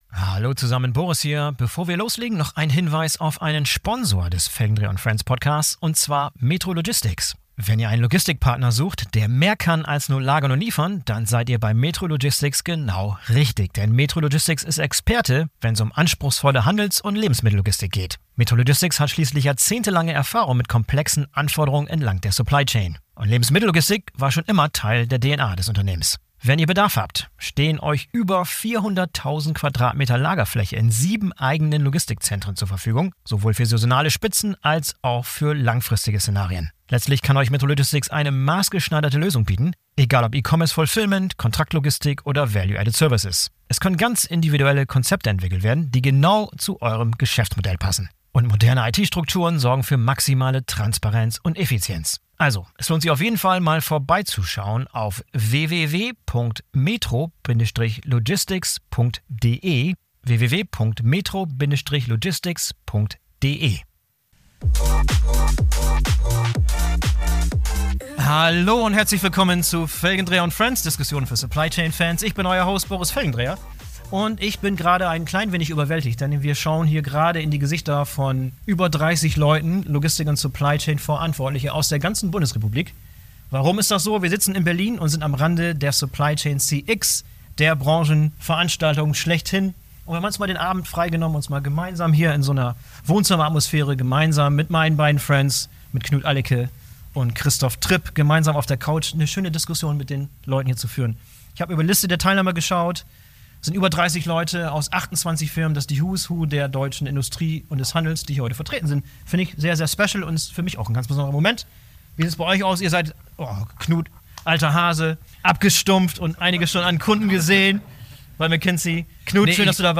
BVL Supply Chain CX Community Diskussion über Resilienz, Cybersecurity und warum KI kein schneller Gamechanger ist